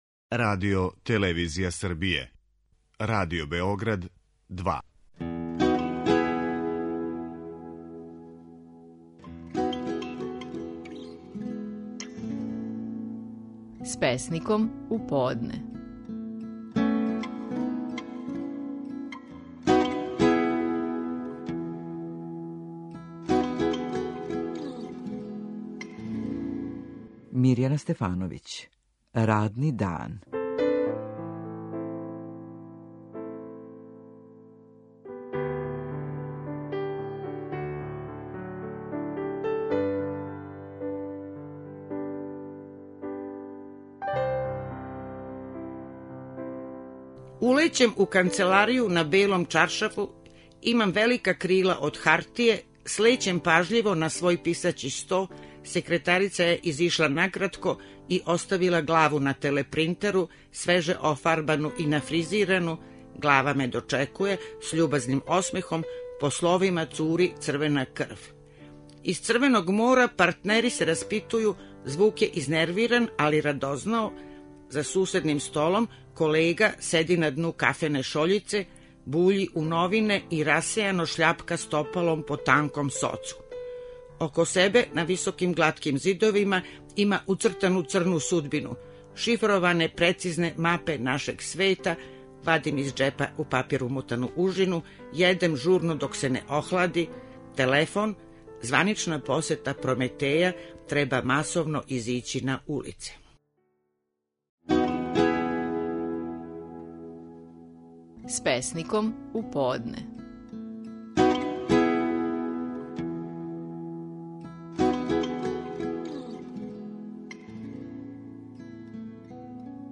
Стихови наших најпознатијих песника, у интерпретацији аутора.
Мирјана Стефановић говори песму: „Радни дан".